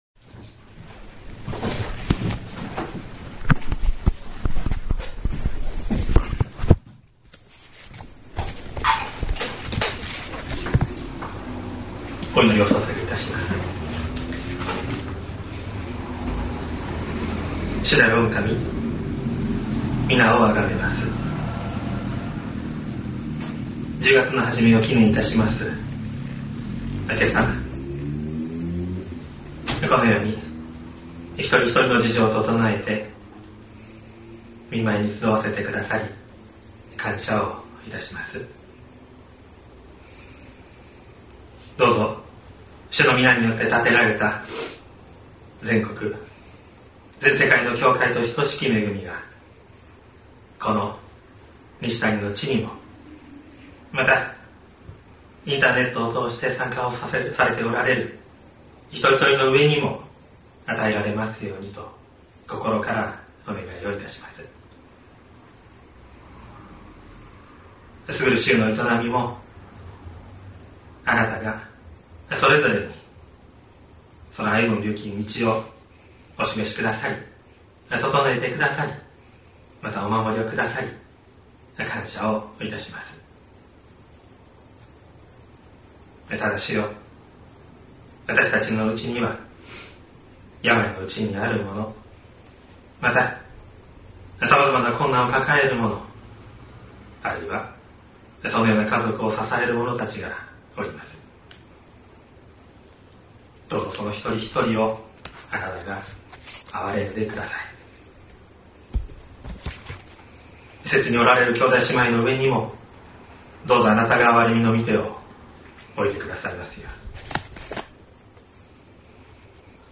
2023年10月01日朝の礼拝「国で最も小さな者でも」西谷教会
説教アーカイブ。
音声ファイル 礼拝説教を録音した音声ファイルを公開しています。